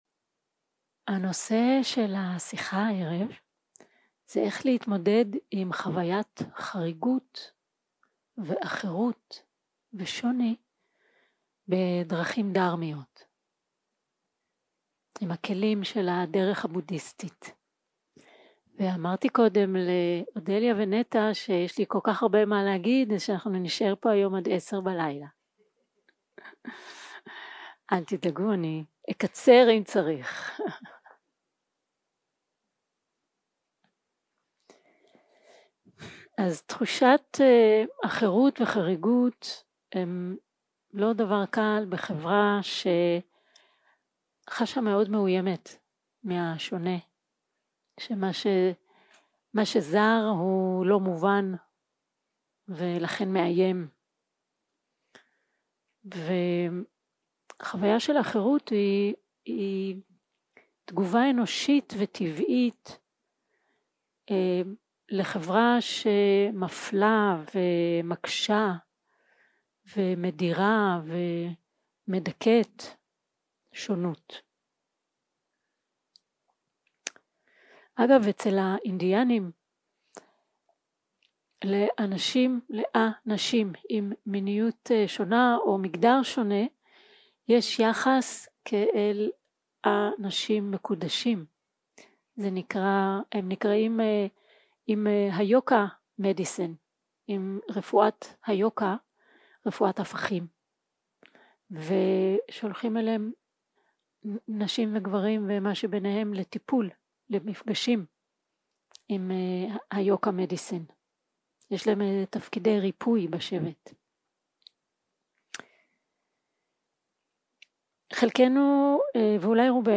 יום 3 - הקלטה 7 - ערב - שיחת דהרמה - איך להתמודד עם תחושת חריגות 0:00 0:00 סוג ההקלטה: Dharma type: Dharma Talks שפת ההקלטה: Dharma talk language: Hebrew